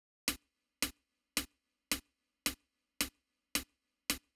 03 Rimshot.wav